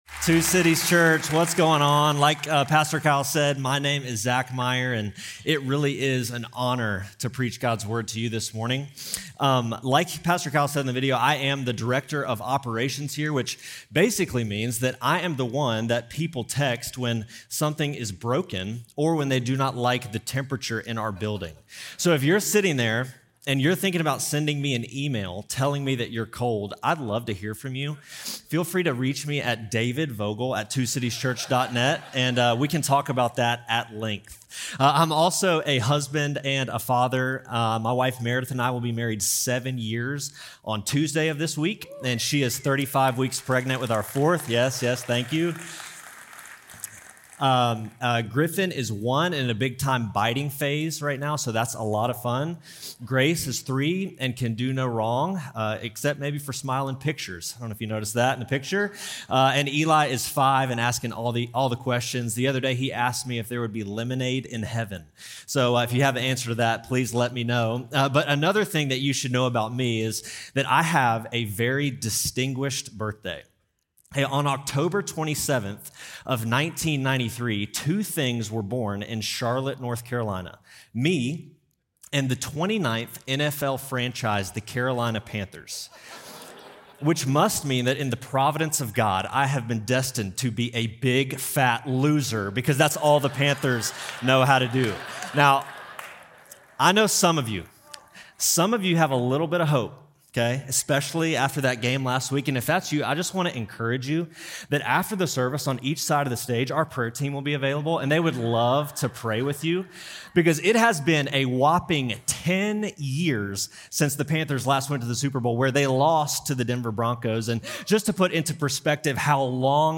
Two Cities Church Podcast - Wrestling with God in the Waiting and the Wanting // Genesis 15-16 - Multipliers: A Study on Abraham | Free Listening on Podbean App